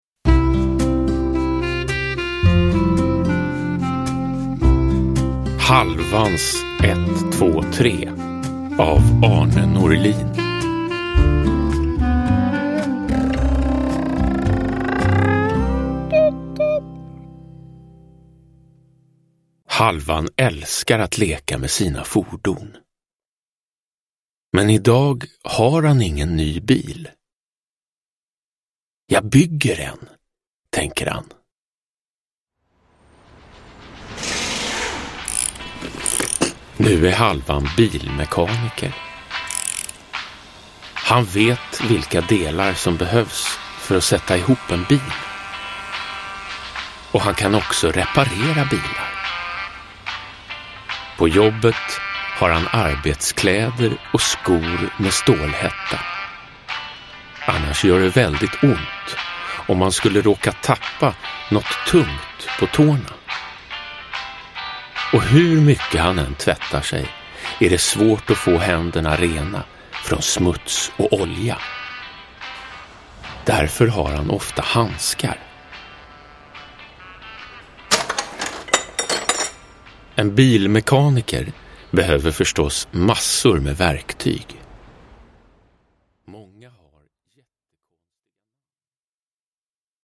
Halvans 1-2-3 – Ljudbok – Laddas ner
Uppläsare: Jonas Karlsson